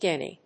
/ˈdɛni(米国英語), ˈdeni:(英国英語)/